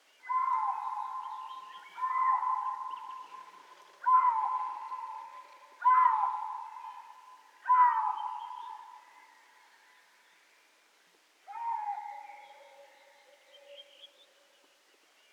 Marderhund Geräusche
Marderhund-Geraeusche-Wildtiere-in-Europa.wav